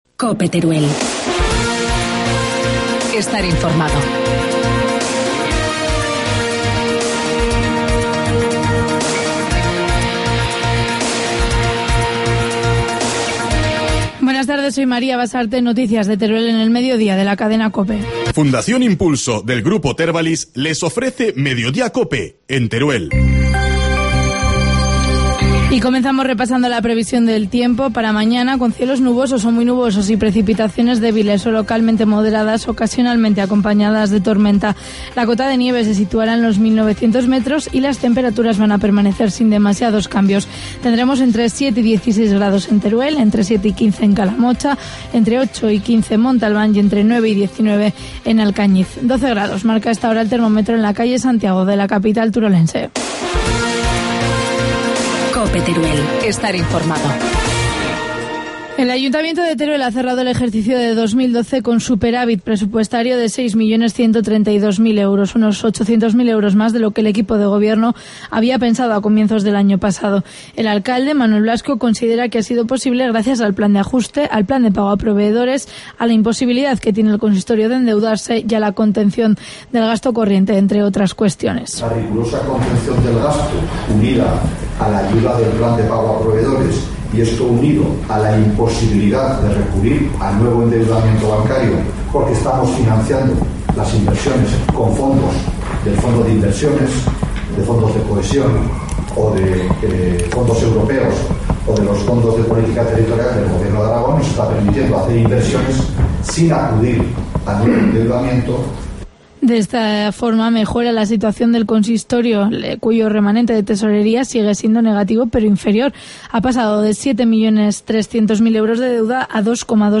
Informativo mediodía, martes 26 de marzo